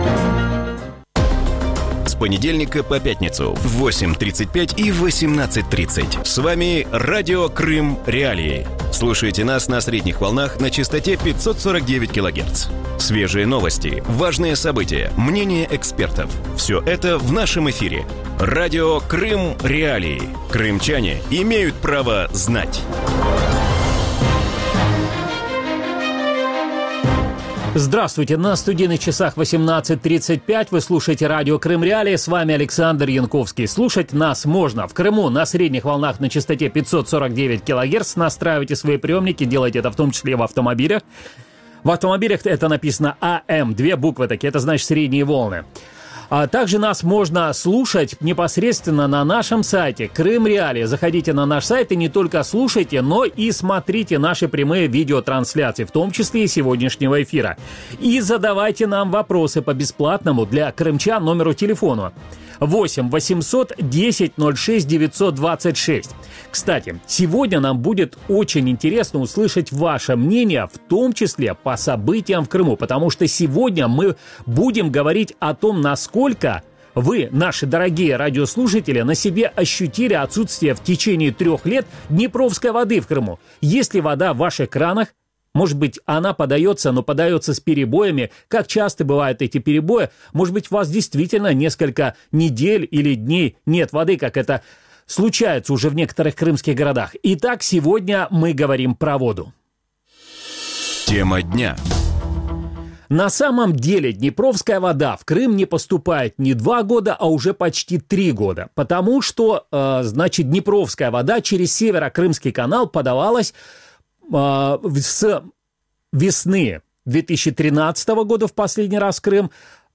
В вечернем эфире Радио Крым.Реалии обсуждают проблемы с водой на востоке Крыма. Сколько воды осталось в крымских водохранилищах, к чему может привести июльская жара и есть ли надежда на создание бесперебойной системы водообеспечения в Крыму?